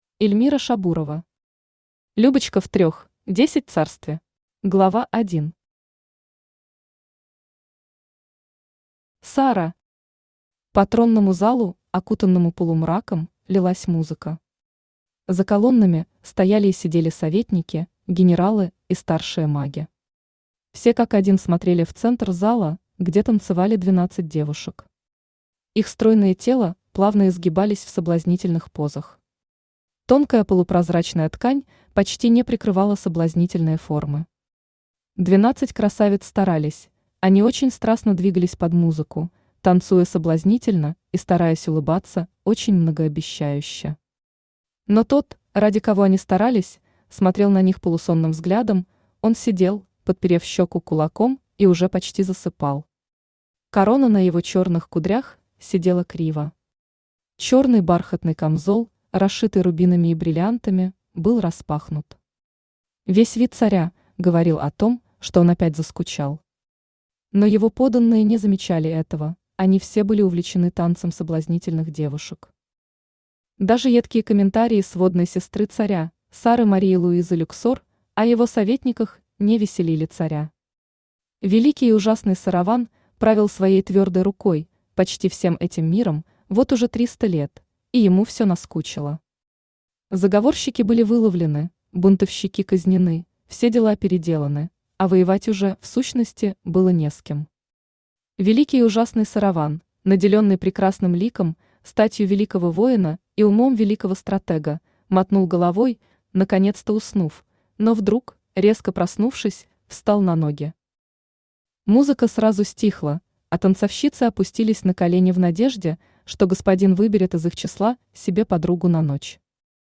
Аудиокнига Любочка в 3\10 царстве | Библиотека аудиокниг
Aудиокнига Любочка в 3\10 царстве Автор Эльмира Шабурова Читает аудиокнигу Авточтец ЛитРес.